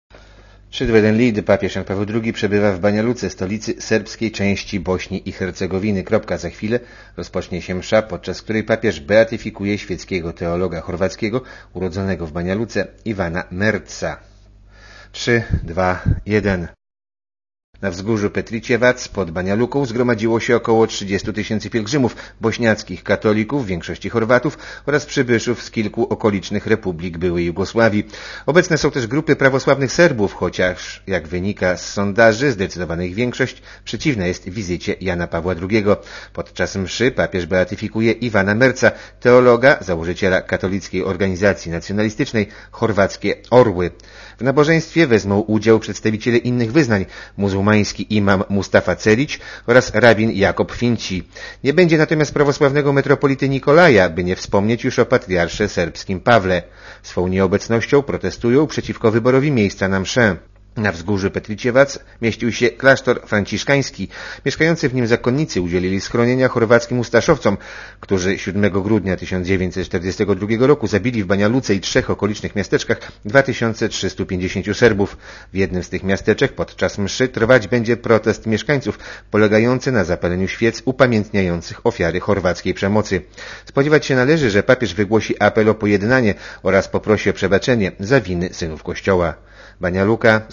Komentarz audio (352Kb)